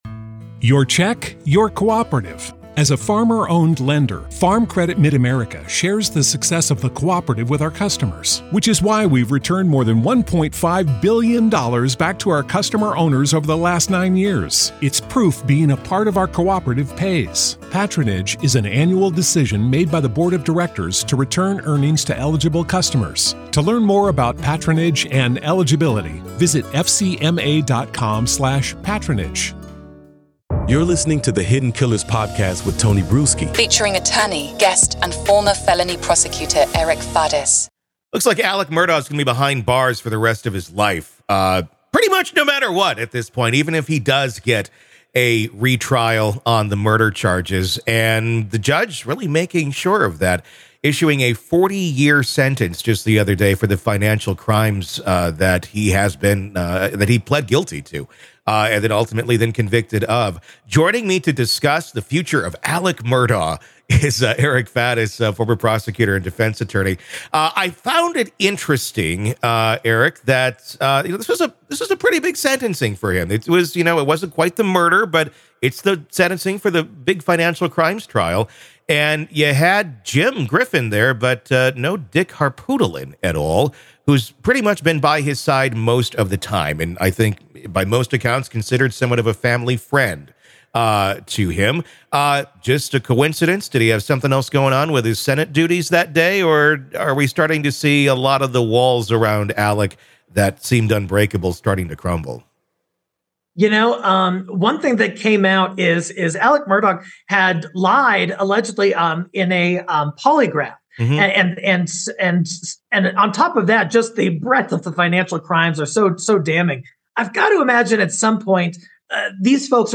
Despite Murdaugh's looming life sentence, the conversation sheds light on the nuanced implications of his...